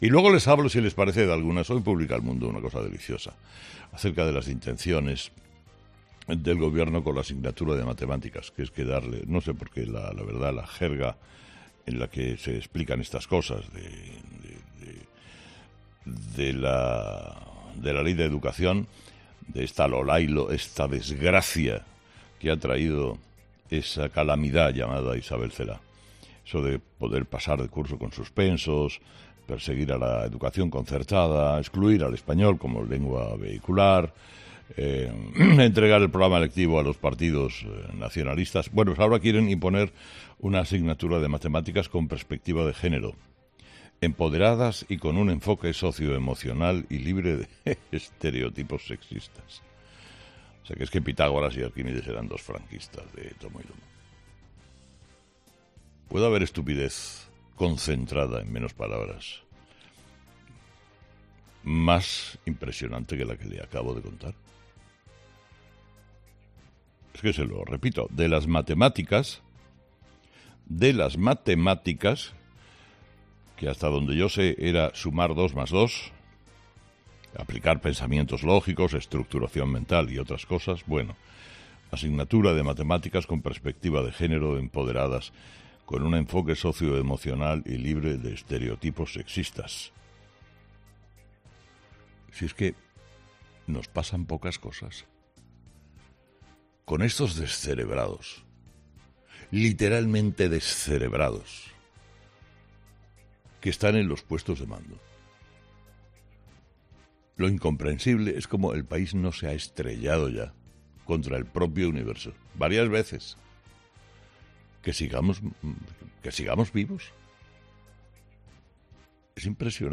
Herrera en su monólogo sobre la nueva ley de educación